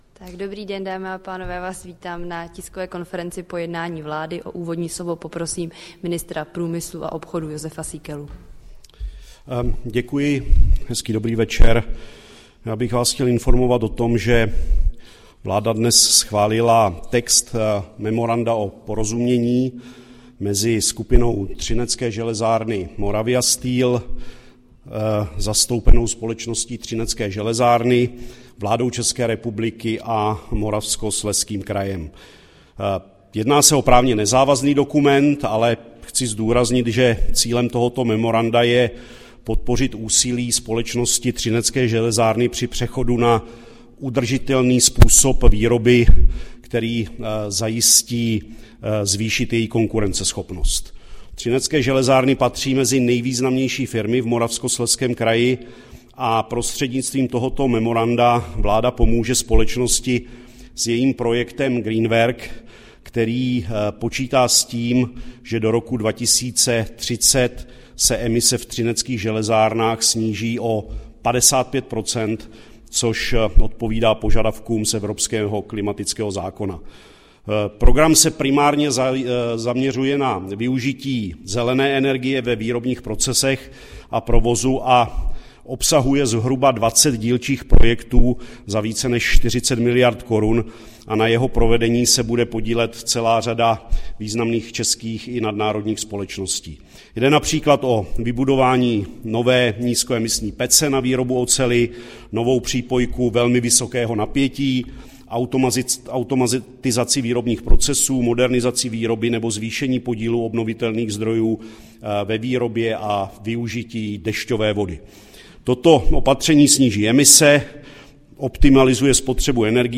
Tisková konference po jednání vlády, 28. února 2024